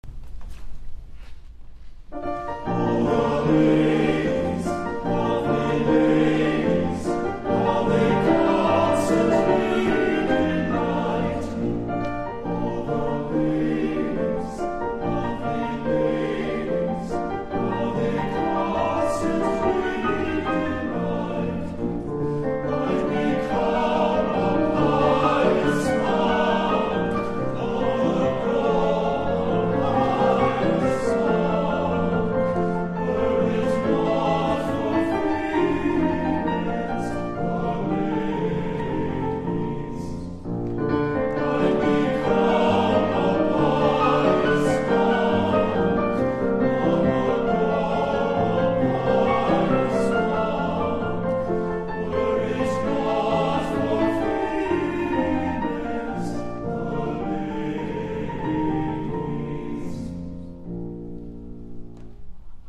Accompaniment:      With Piano
Music Category:      Choral
Performed at the Choral Arts Symposium